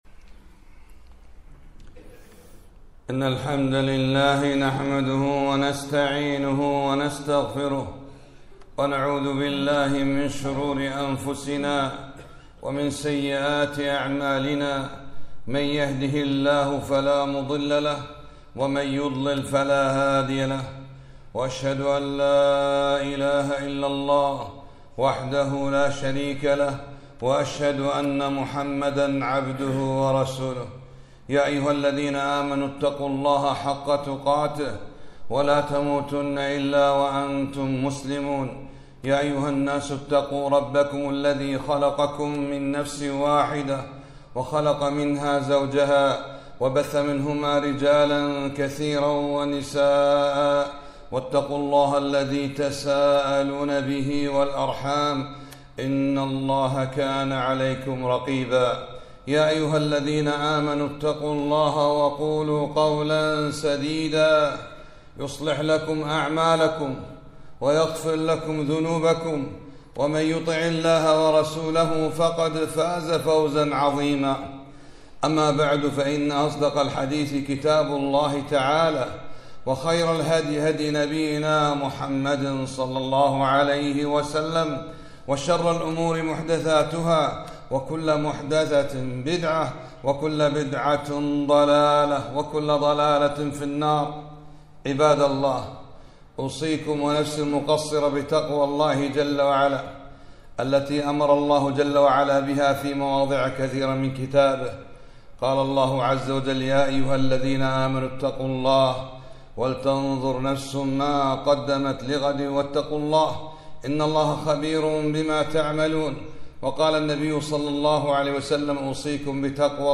خطبة - من يرد الله به خيرًا يفقه في الدين